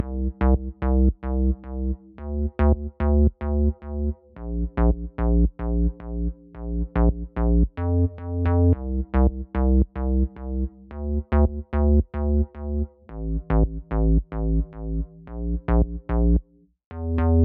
热带包4次方低音
Tag: 110 bpm House Loops Bass Synth Loops 2.94 MB wav Key : Unknown